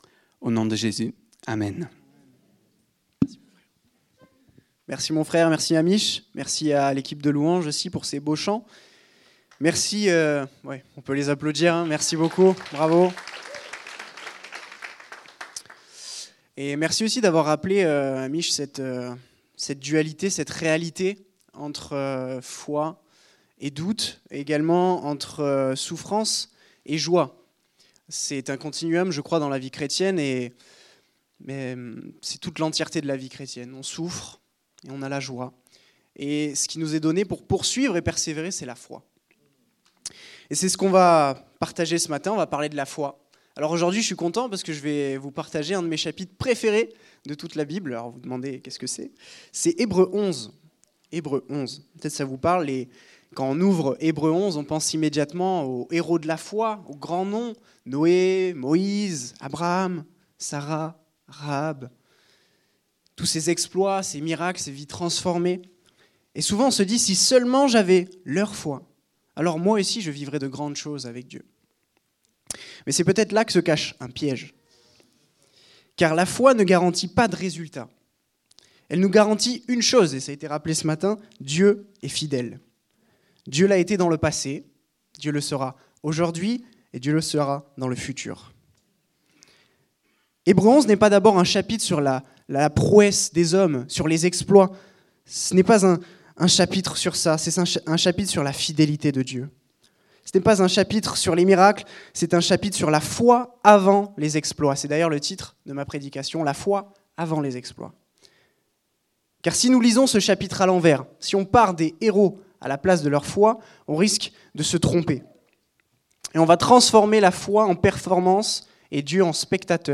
Culte du dimanche 22 février 2026, prédication